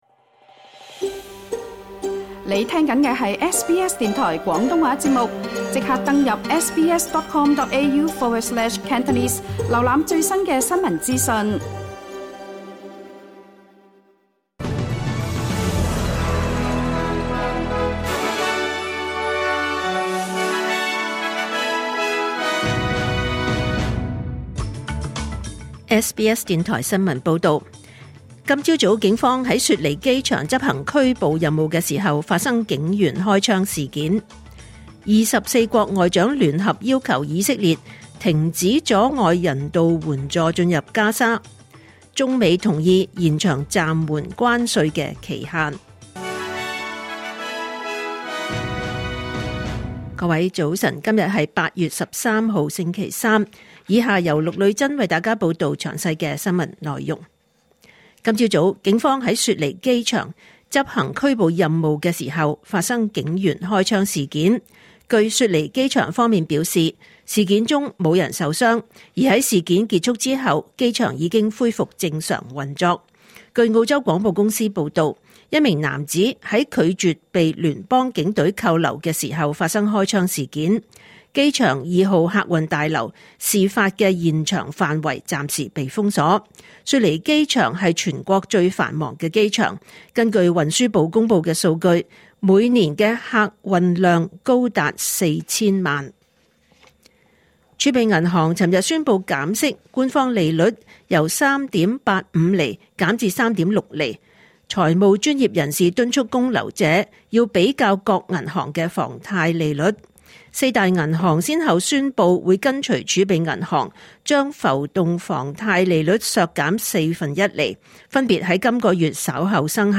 2025年8月13日SBS廣東話節目九點半新聞報道。